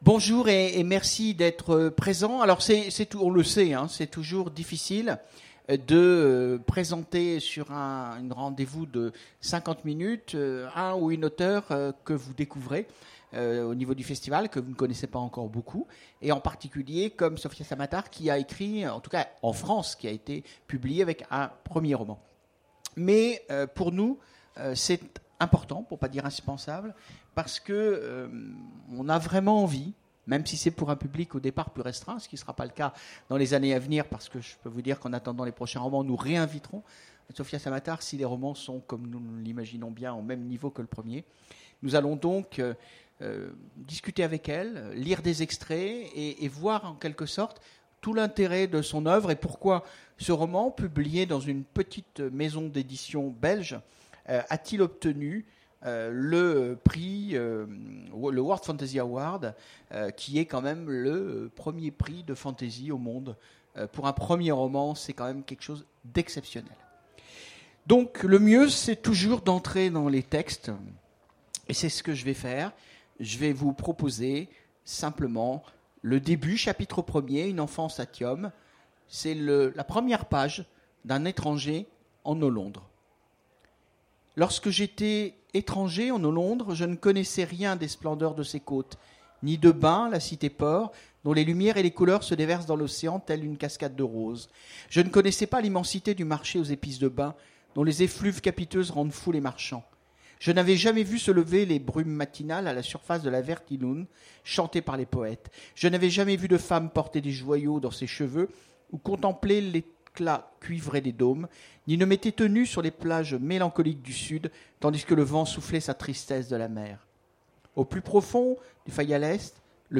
Imaginales 2017 : Entretien avec Sofia Samatar
- le 31/10/2017 Partager Commenter Imaginales 2017 : Entretien avec Sofia Samatar Télécharger le MP3 à lire aussi Sofia Samatar Genres / Mots-clés Rencontre avec un auteur Conférence Partager cet article